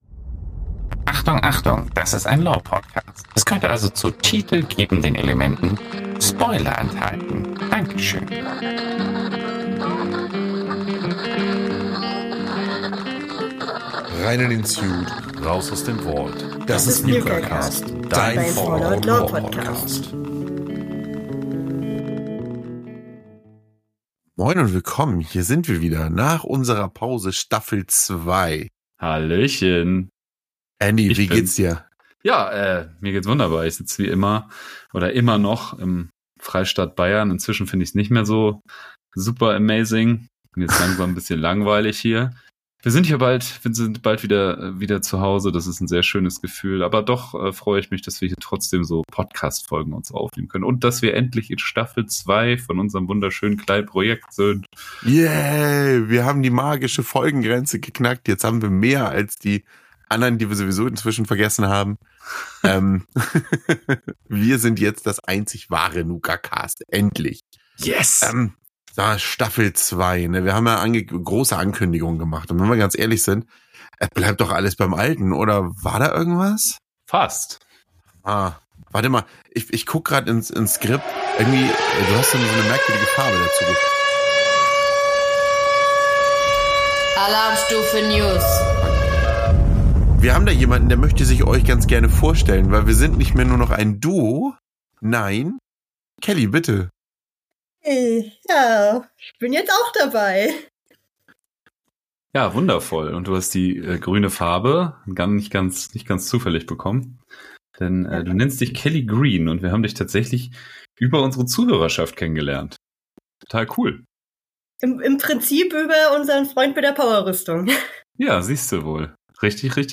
Wir haben Zuwachs bekommen und besprechen aller erstmal News rund um das Franchise und gehen dann so langsam mal Fallout 2 an! Hinten heran gehängt kommt noch ein 3er Interview zum Fallout Tabletop